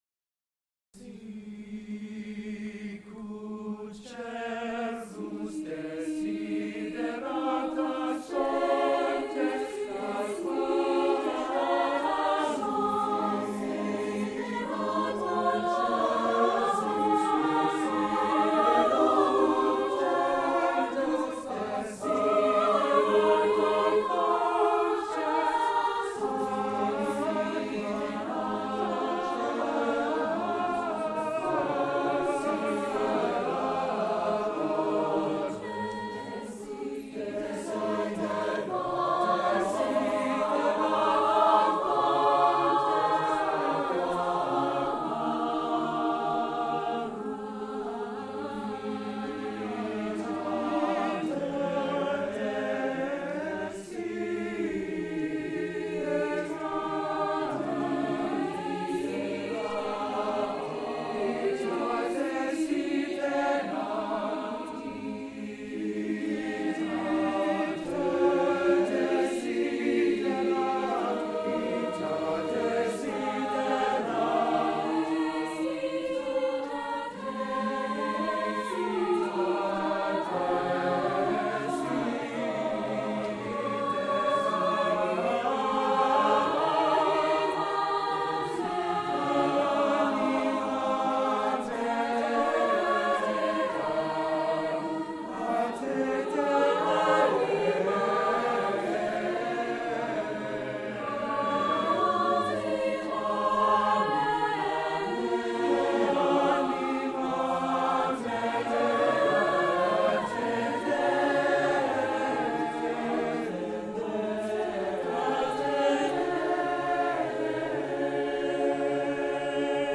Wednesday, November 12, 2010 • Roberts-Dubbs Auditorium, Brookline High School
Camerata